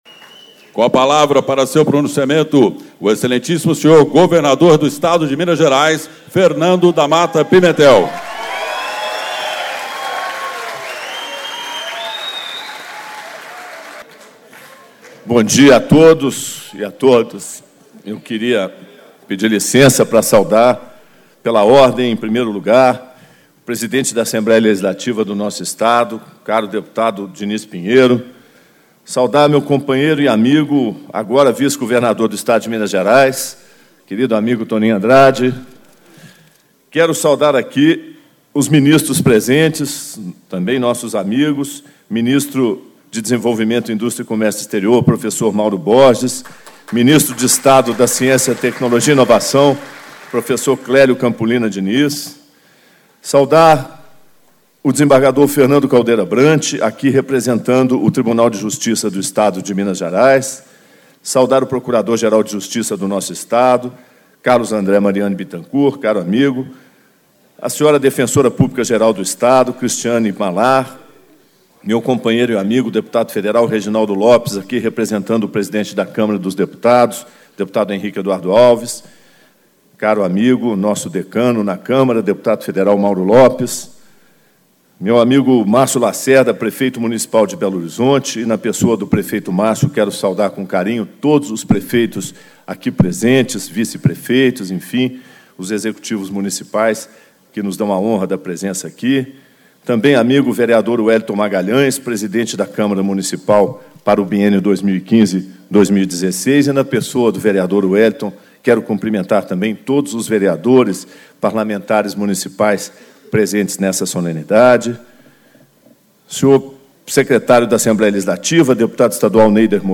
Pronunciamento de Posse - Fernando Damata Pimentel, Governador do Estado de Minas Gerais
Reunião Solene destinada à Posse do Governador do Estado de Minas Gerais
Discursos e Palestras